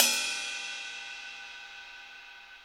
• Long Crash Cymbal Single Shot E Key 04.wav
Royality free crash cymbal audio clip tuned to the E note. Loudest frequency: 5031Hz
long-crash-cymbal-single-shot-e-key-04-BDw.wav